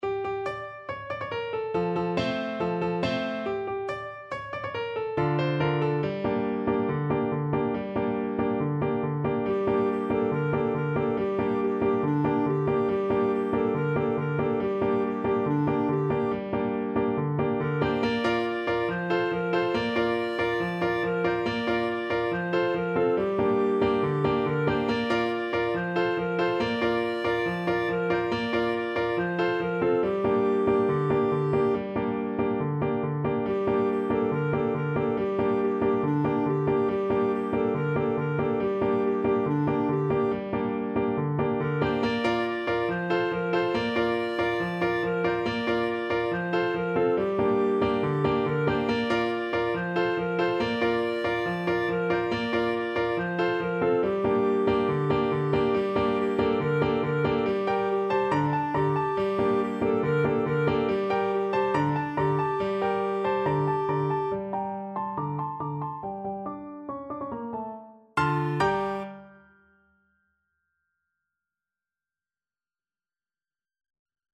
Clarinet
G minor (Sounding Pitch) A minor (Clarinet in Bb) (View more G minor Music for Clarinet )
Very Fast =c.140
4/4 (View more 4/4 Music)
Classical (View more Classical Clarinet Music)